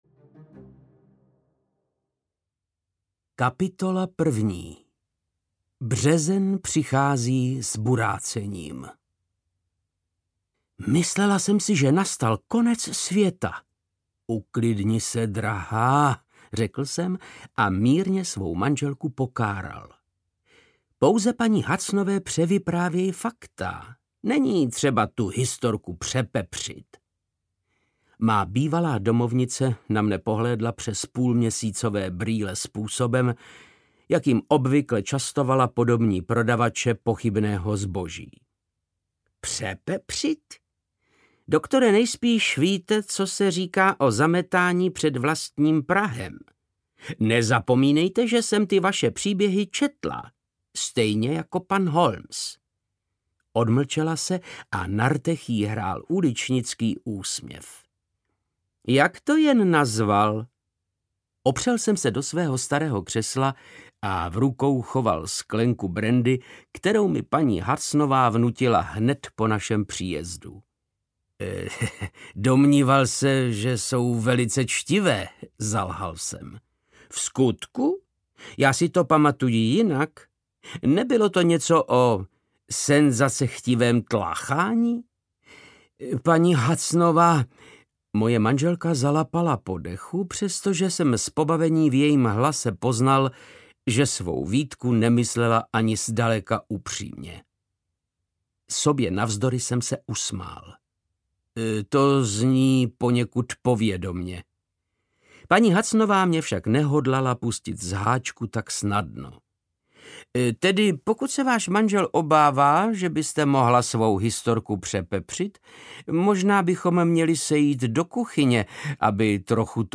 Sherlock Holmes a Pláč nevinných audiokniha
Ukázka z knihy
• InterpretVáclav Knop